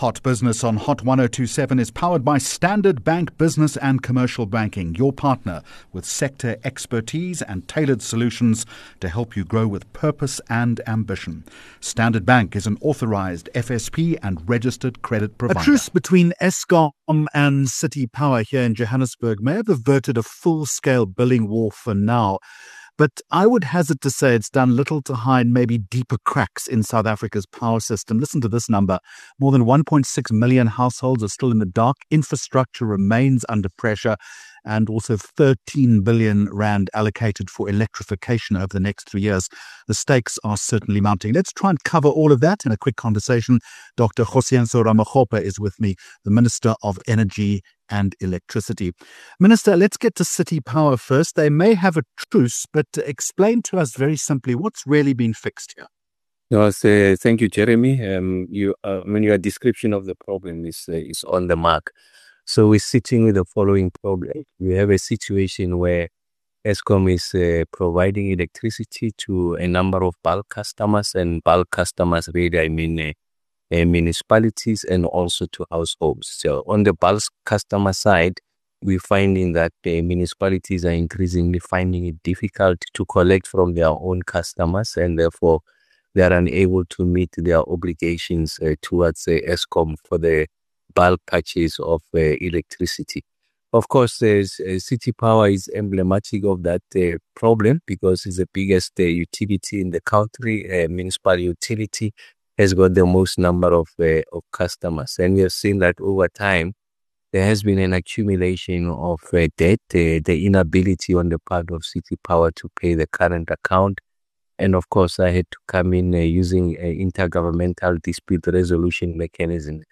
21 Jul Hot Business Interview - Dr Ramokgopa 21 July 2025